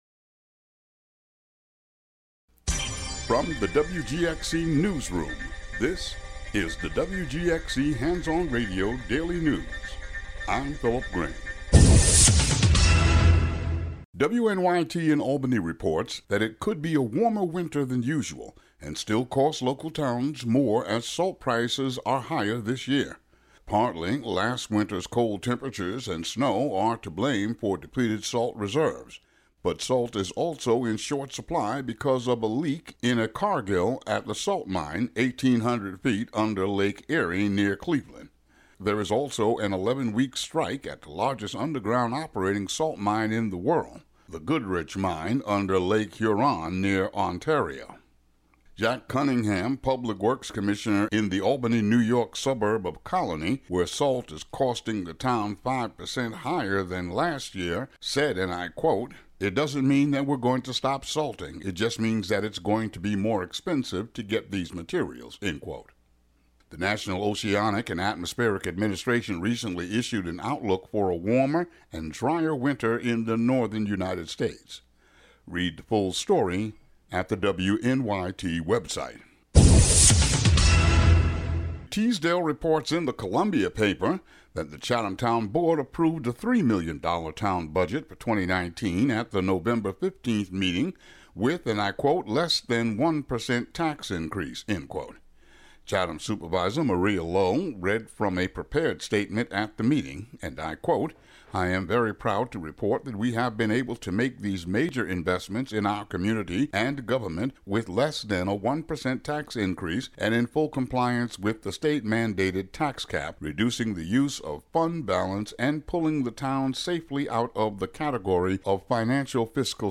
Today's local news.